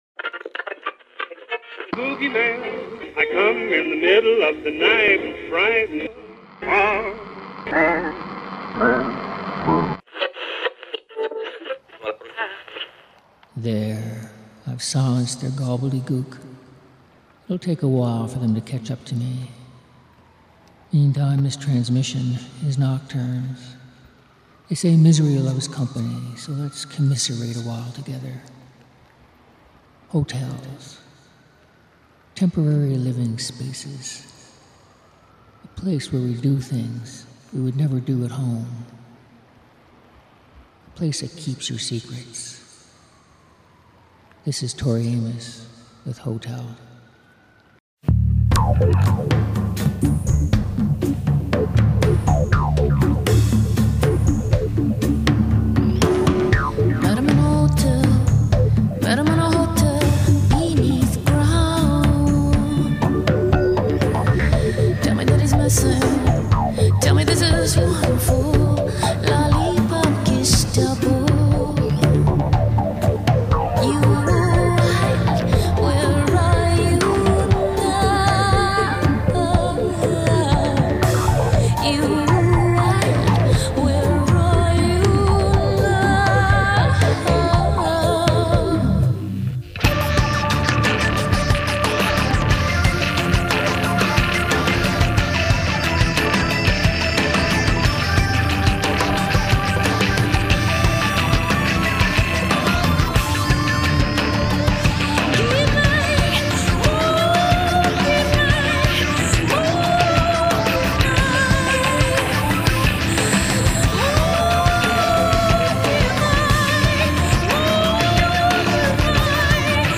Music For Nighttime Listening